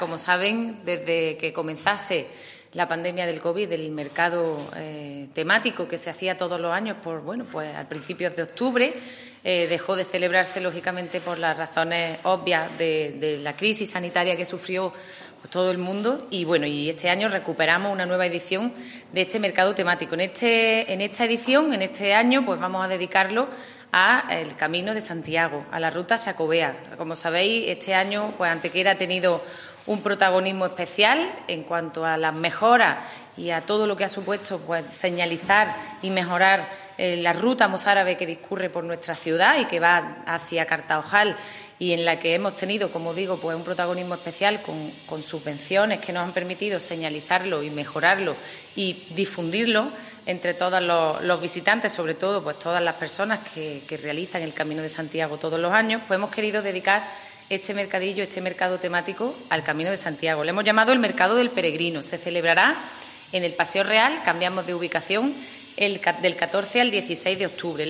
La teniente de alcalde delegada de Turismo, Patrimonio Histórico, Políticas de Empleo y Comercio, Ana Cebrián, ha presentado una nueva edición de una popular iniciativa que llevaba sin poder realizarse desde el año 2019 como consecuencia de la pandemia. Se trata del mercado temático, ambientado este año en el Camino de Santiago con el sobrenombre de "Mercado del Peregrino".
Cortes de voz